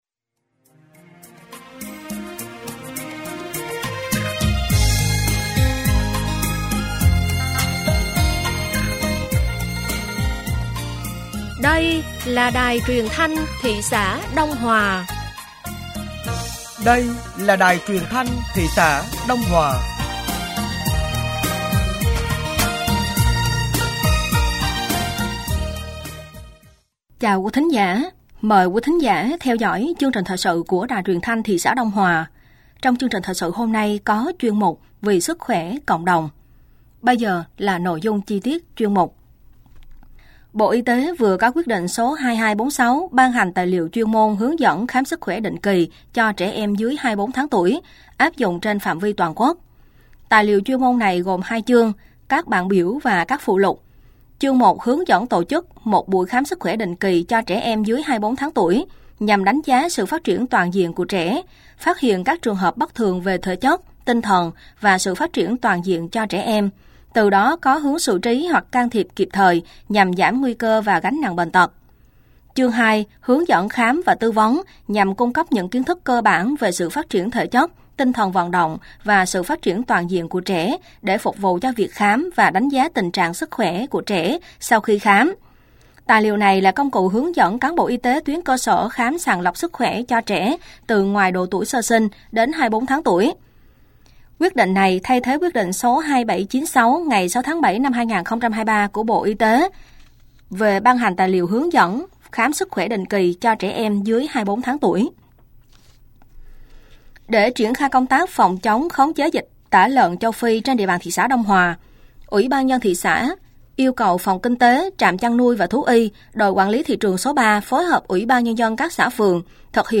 Thời sự tối ngày 13 và sáng ngày 14 tháng 8 năm 2024